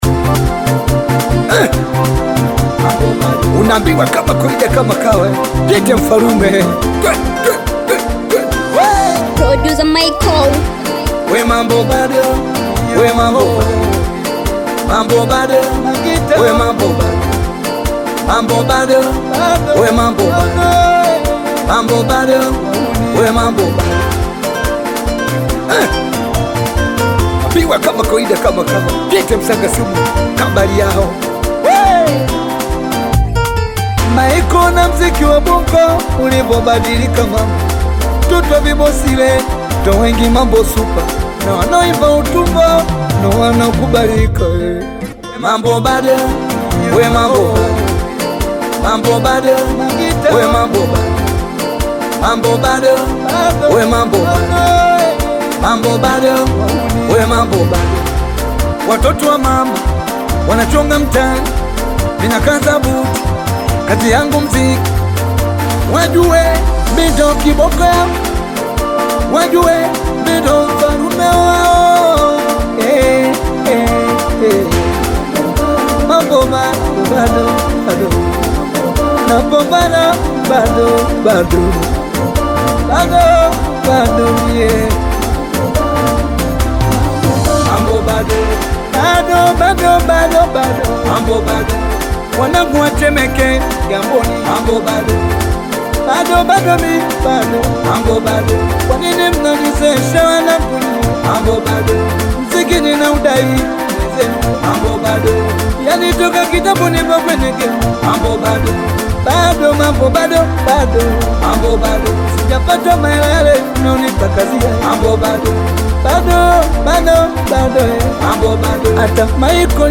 Singeli and bongo flava
African Music